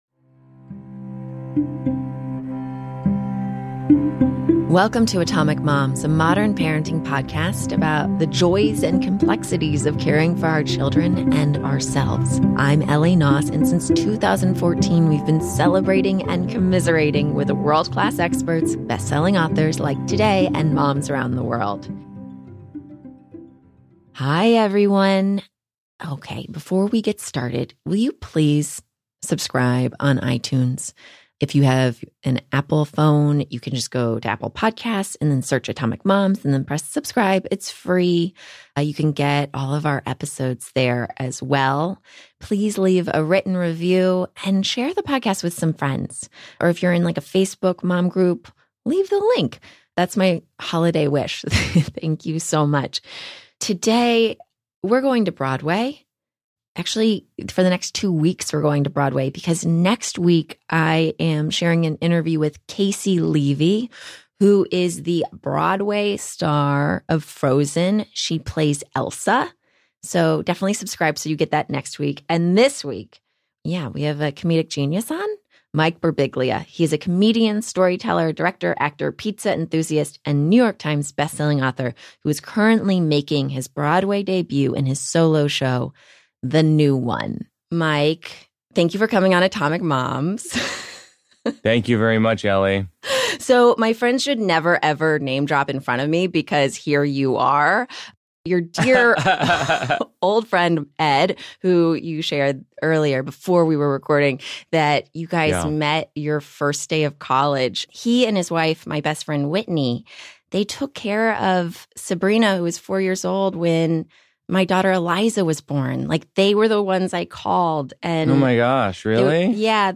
#201: Comedian, director, and bestselling author MIKE BIRBIGLIA chats with host